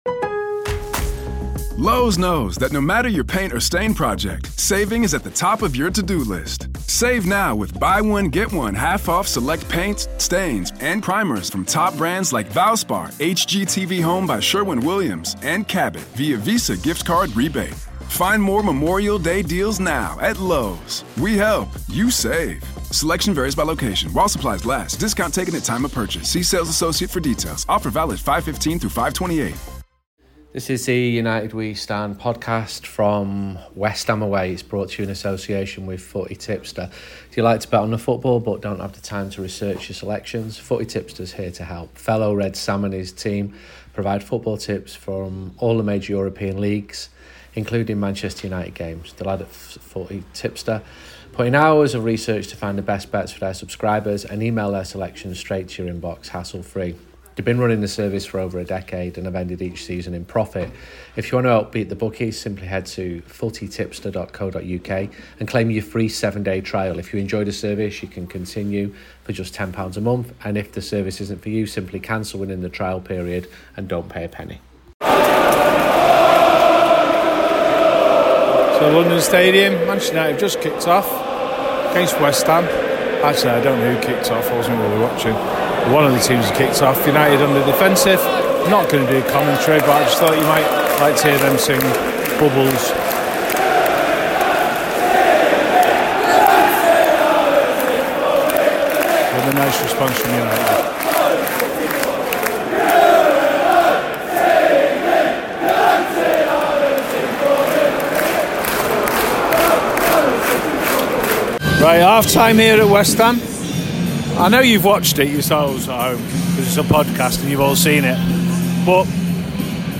With reds and West Ham fans.